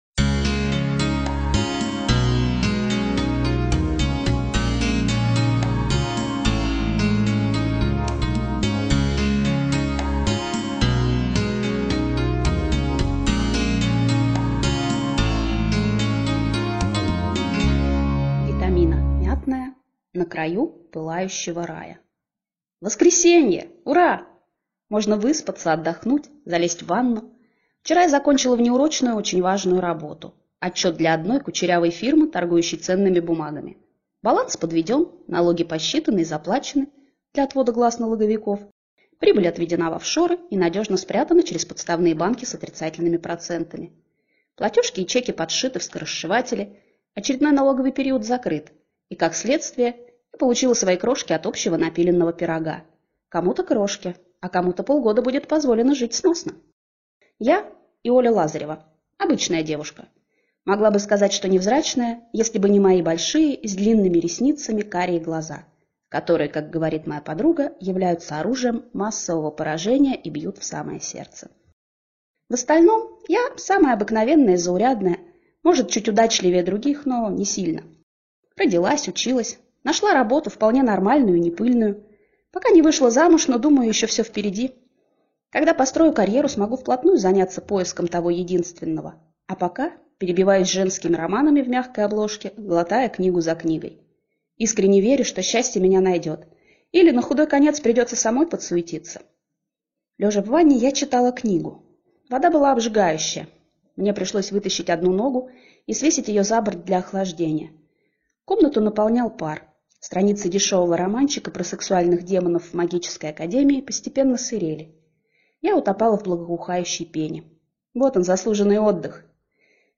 Аудиокнига На краю пылающего Рая | Библиотека аудиокниг